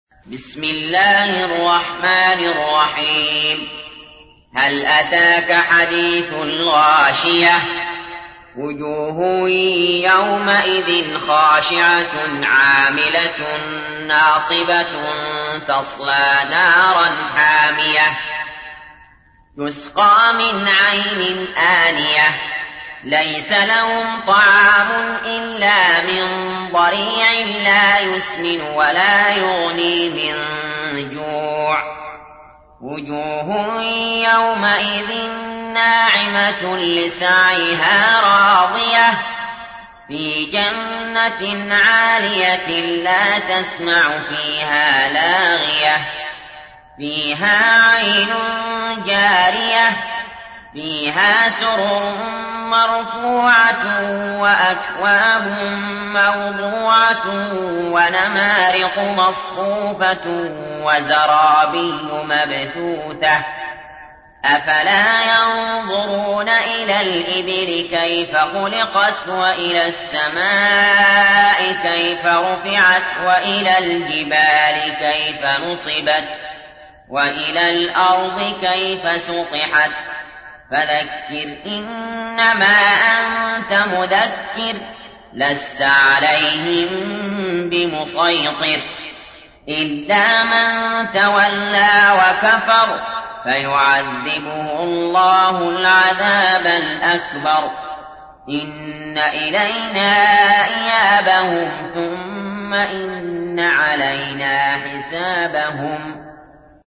تحميل : 88. سورة الغاشية / القارئ علي جابر / القرآن الكريم / موقع يا حسين